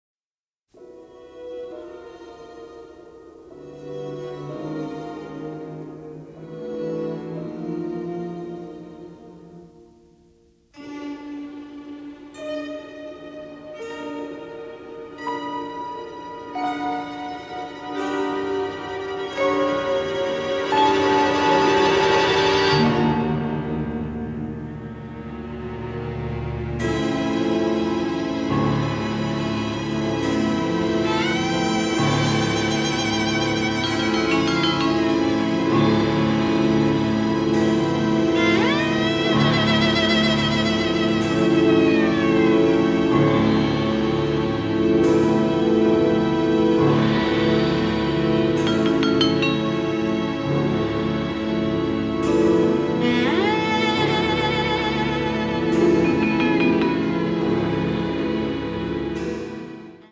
a wonderful romantic horror score
in beautiful pristine stereo